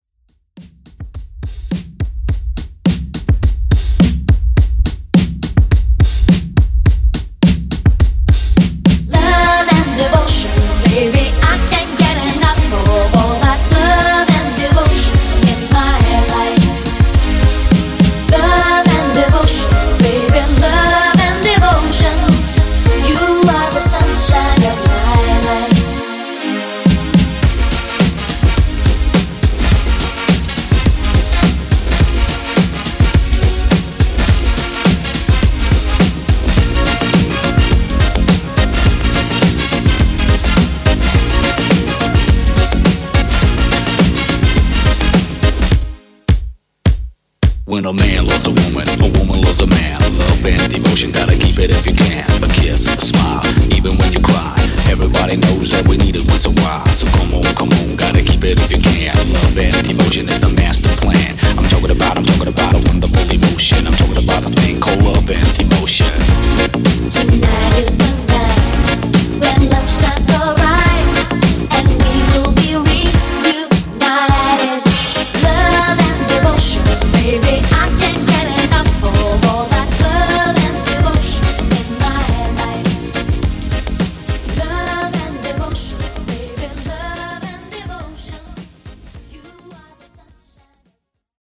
Genres: 80's , RE-DRUM
Clean BPM: 127 Time